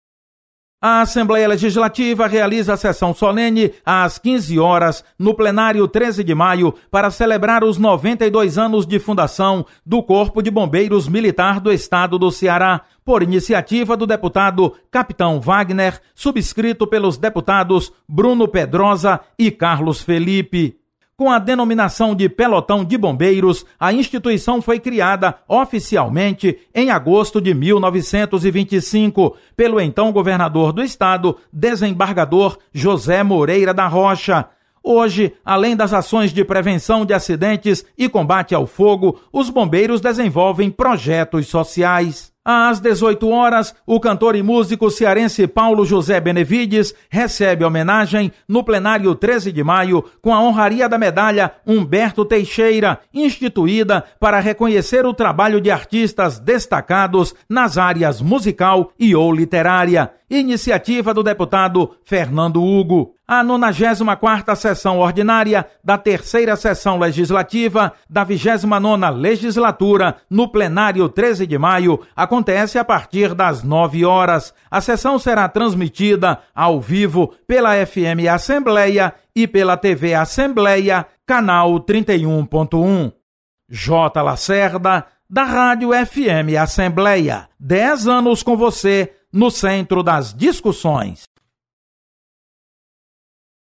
Acompanhe as atividades de hoje da Assembleia Legislativa com o repórter